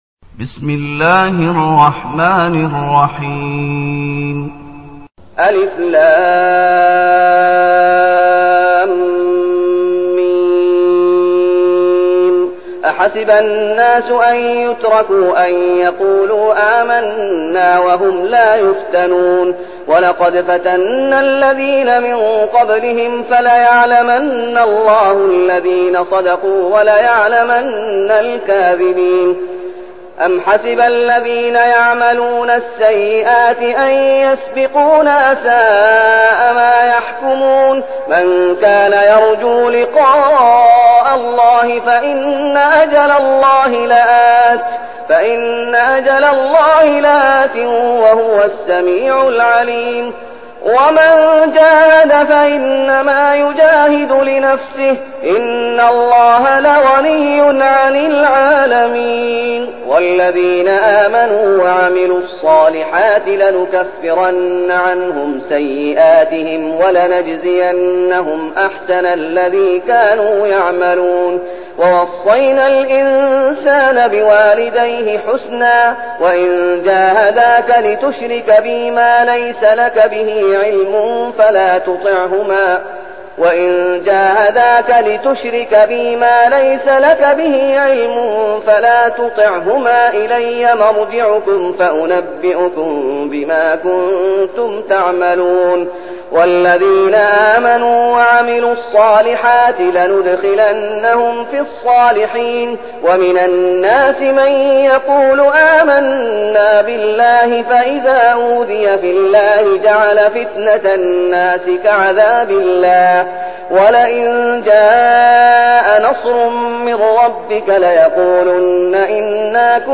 المكان: المسجد النبوي الشيخ: فضيلة الشيخ محمد أيوب فضيلة الشيخ محمد أيوب العنكبوت